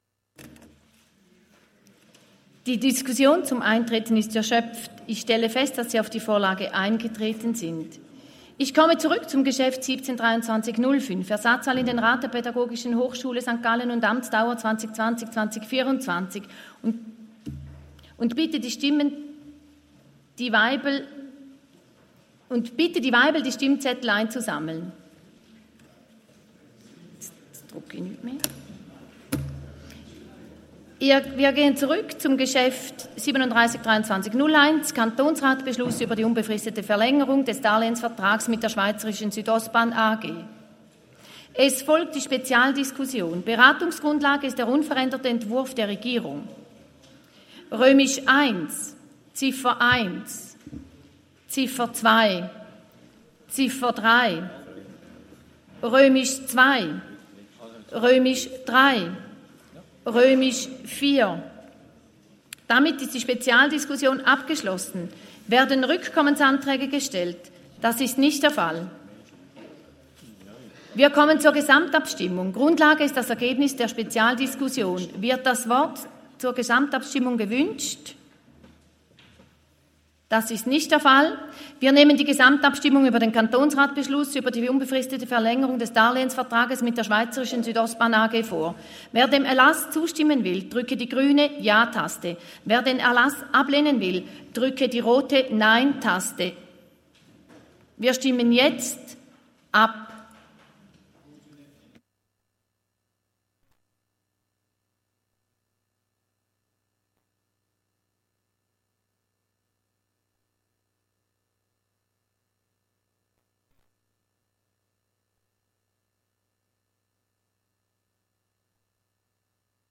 Session des Kantonsrates vom 27. bis 29. November 2023, Wintersession
27.11.2023Wortmeldung